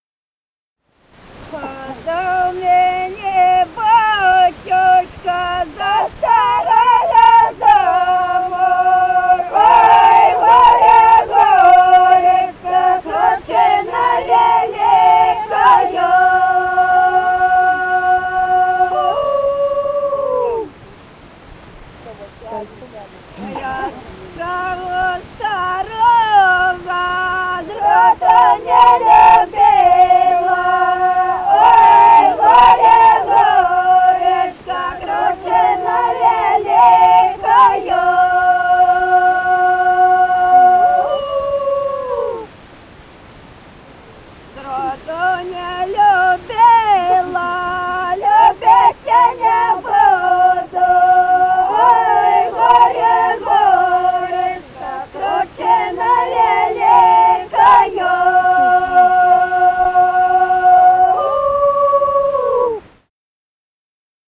«Отдав мене батюшка» (карагодная).
Записали участники экспедиции